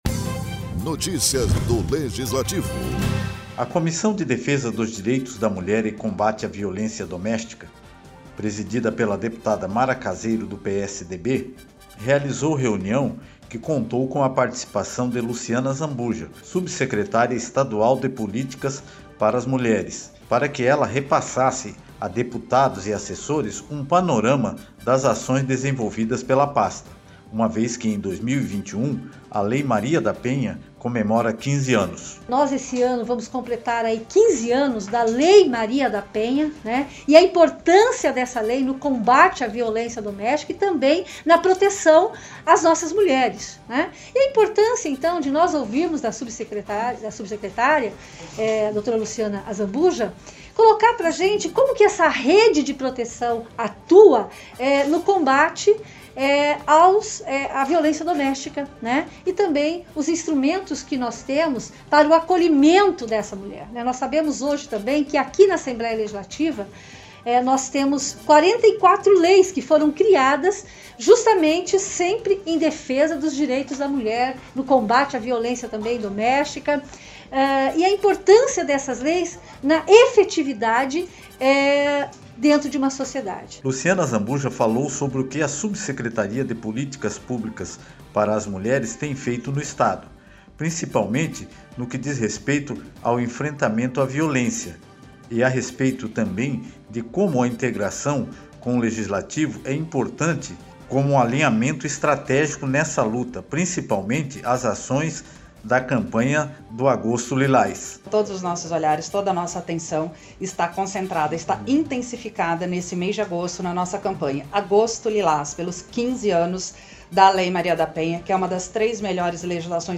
Como parte dos eventos relativos aos 15 anos da Lei Maria da Penha , marco no combate ao feminicídio e também para o lançamento da Campanha Agosto Lilás, a Assembleia Legislativa realizou uma reunião semi-presencial onde a sub-Secretaria de Estado de Cidadania, Cultura e Subsecretaria de Políticas Públicas para Mulheres, Luciana Azambuja apresentou um relato sobre o enfrentamento ao feminicídio e a violência contra a mulher.